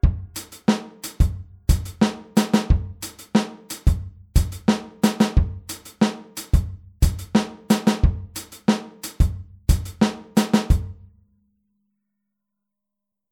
Hier spielen wir den Offbeat mit der rechten Hand wieder auf dem HiHat.
Groove02a-16off.mp3